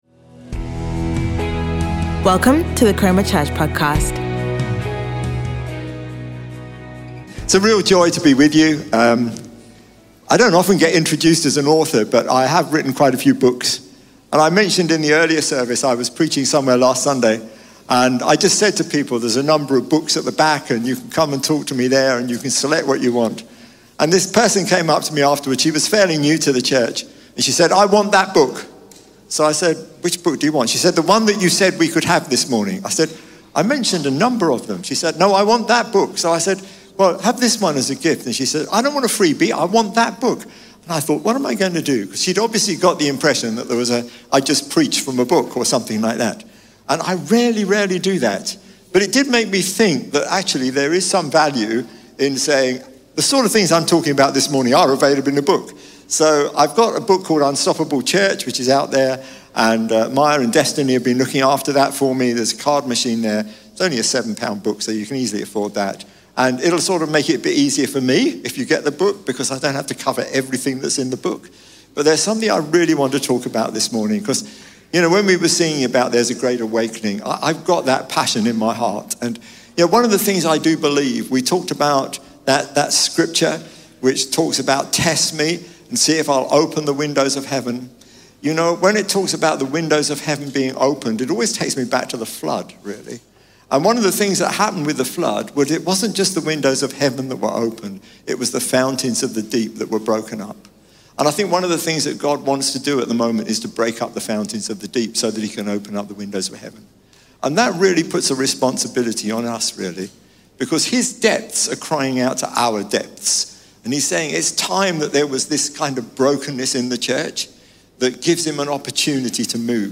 Chroma Church - Sunday Sermon